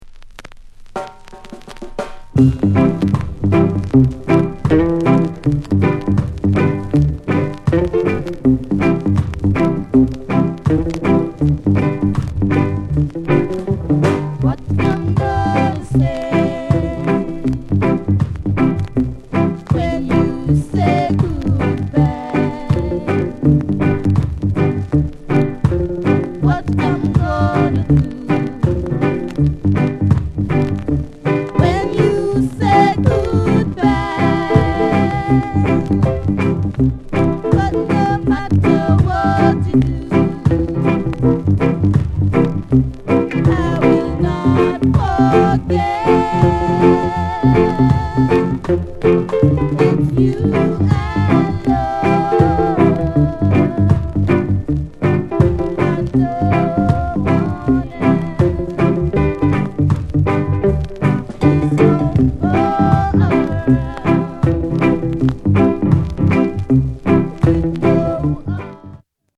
ROCKSTEADY